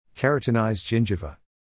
[‘kerətənaɪzd ‘ʤɪnʤɪvə][‘кэрэтэнайзд ‘джинджэвэ]кератинизированная десна